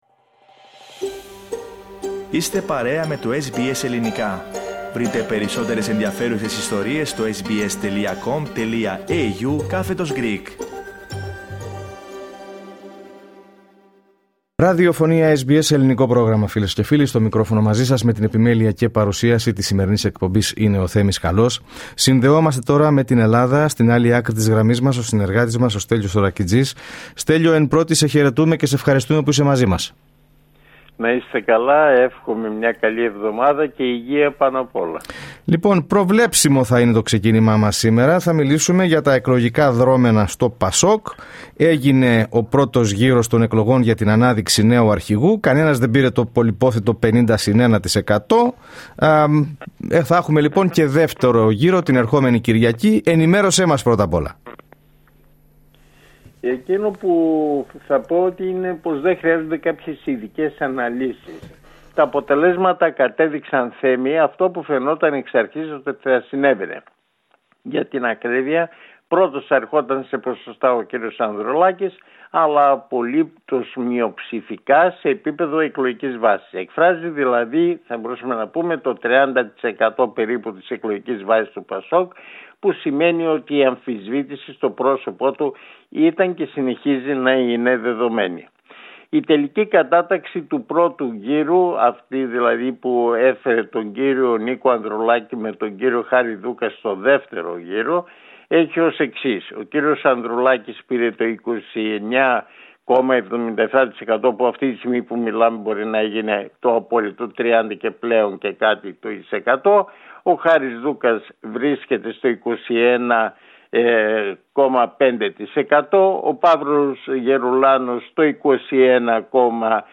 Ακούστε την εβδομαδιαία ανταπόκριση από την Ελλάδα